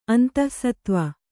♪ antahsatva